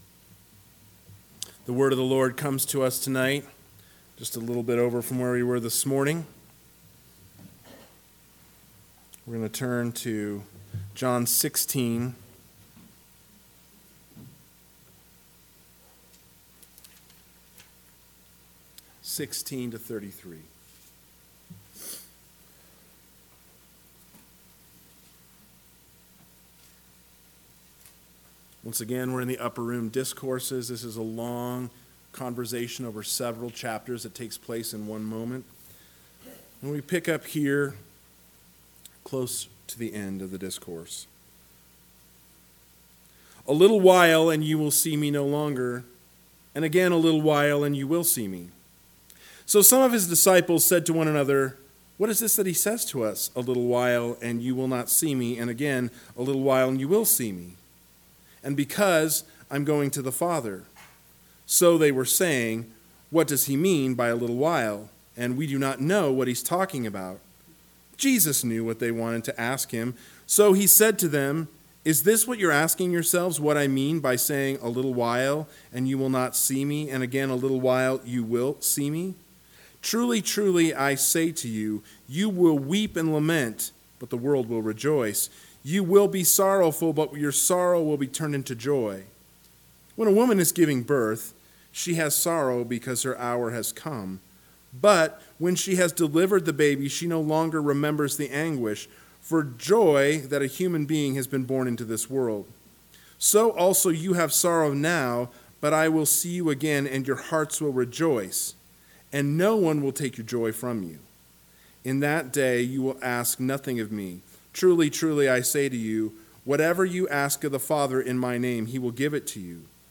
PM Sermon